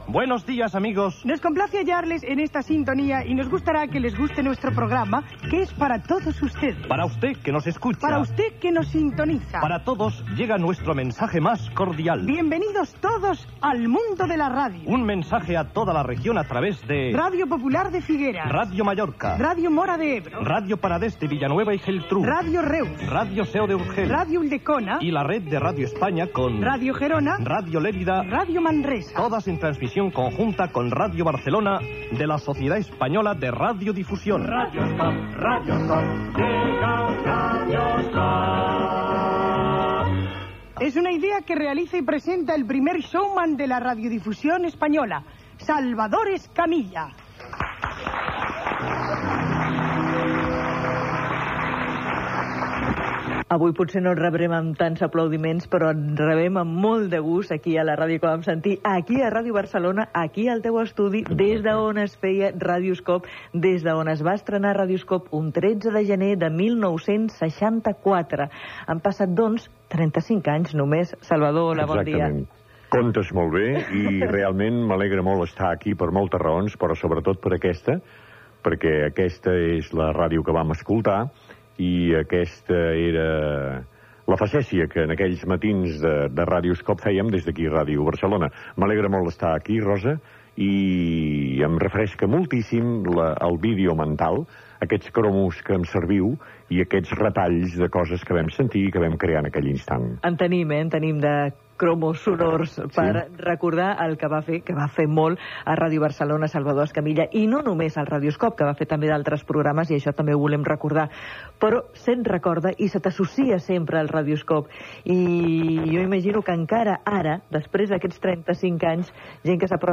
Careta delcprograma "Radio-scope" i entrevista a Salvador Escamilla, 35 anys després de l'estrena del programa